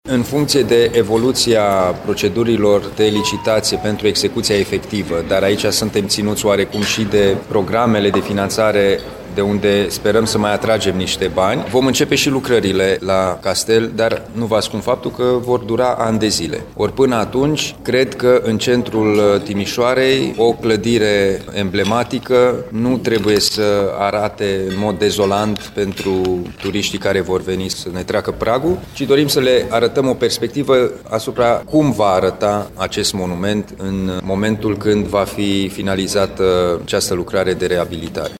Din păcate, lucrările se vor întinde pe mulți ani, spune președintele Consiliului Județean Timiș, Alin Nica.